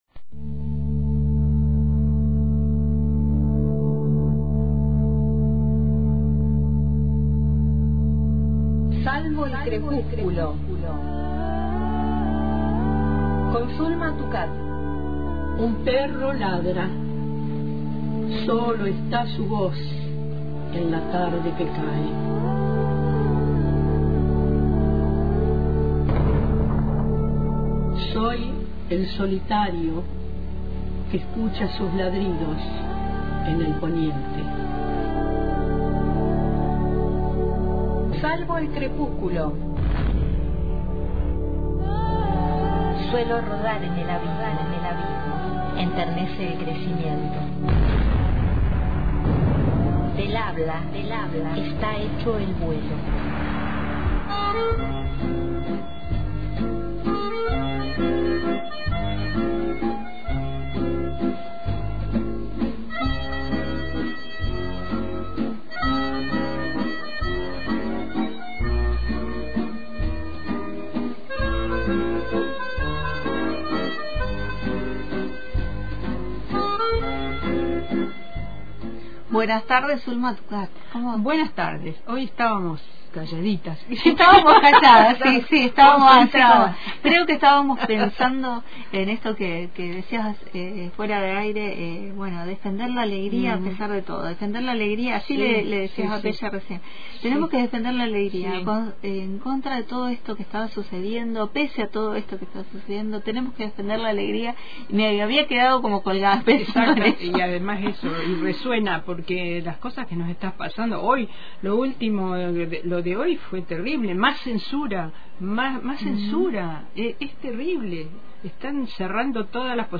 Esuchamos algunas de sus producciones en la propia voz de Mario Benedetti, quien cuenta de qué se trata la escritura del «Haiku».